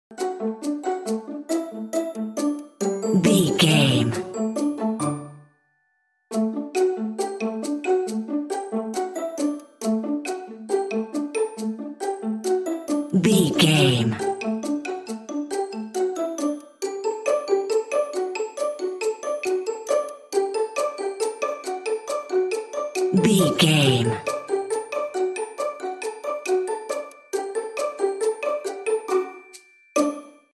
Ionian/Major
D
bouncy
cheerful/happy
playful
uplifting